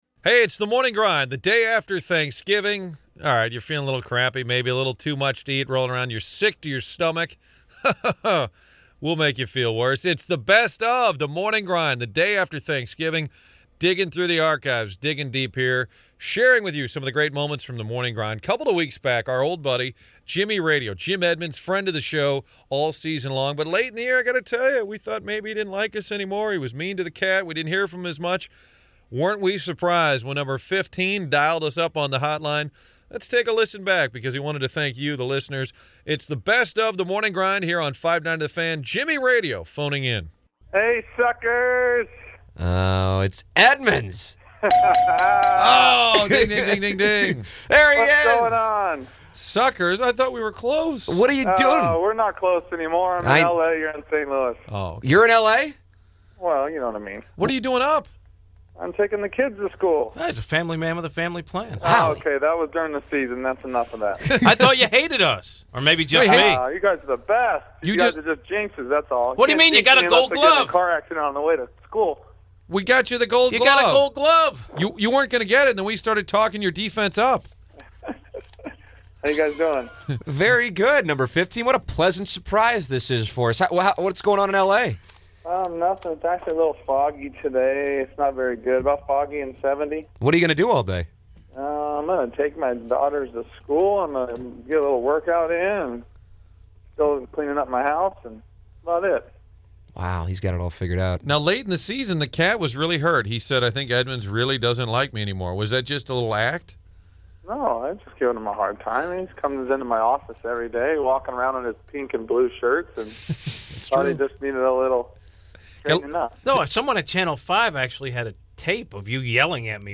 A Morning Grind “best of” show that originally aired the day after Thanksgiving, filled with classic clips.